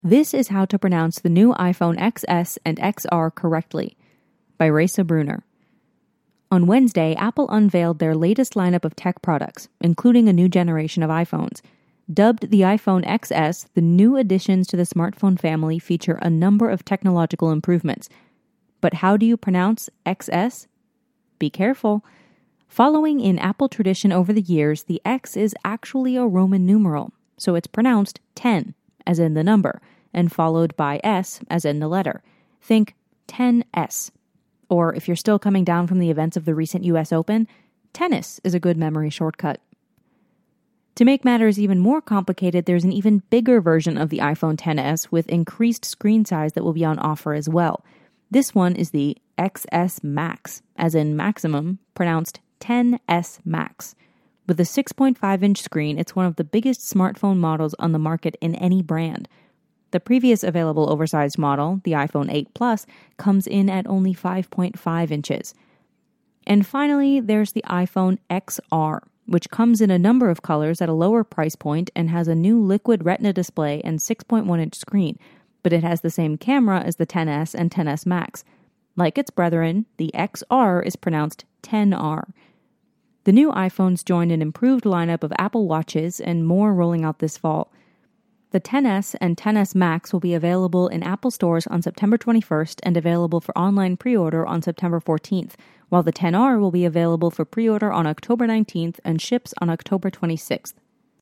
So it's pronounced ten, as in the number — and followed by S, as in the letter.